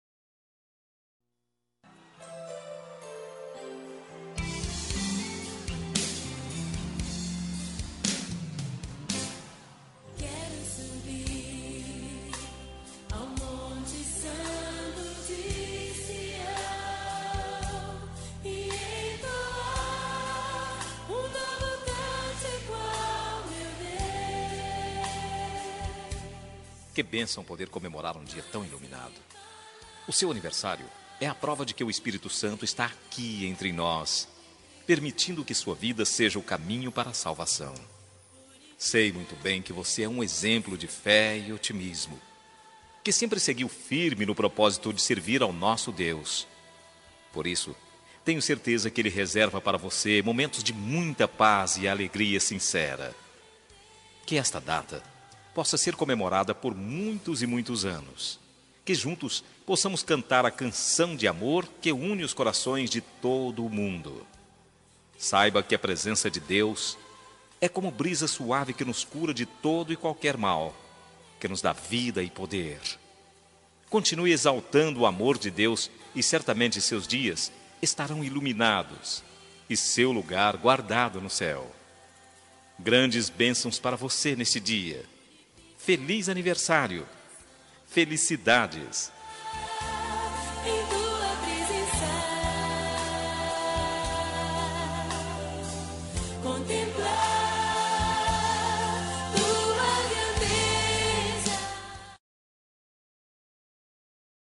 Aniversário Pessoa Especial Gospel – Voz Masculina – Cód: 6050